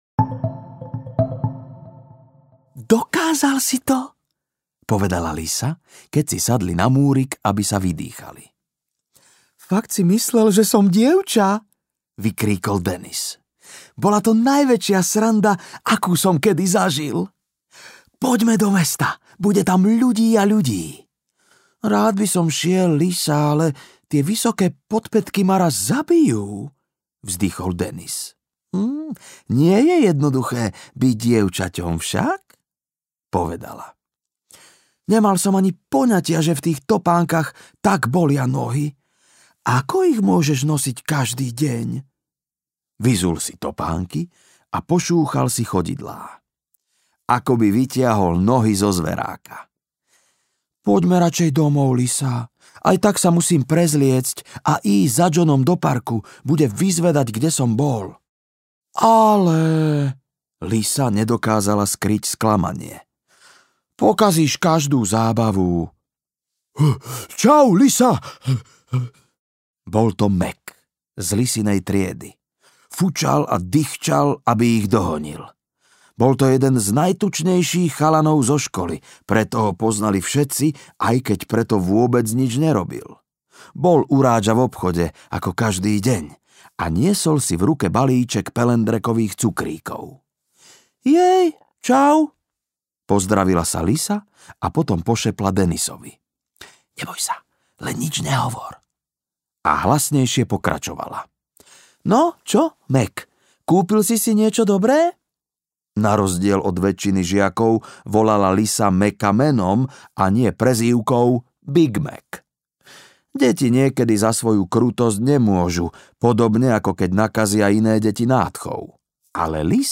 Chalan v sukni audiokniha
Ukázka z knihy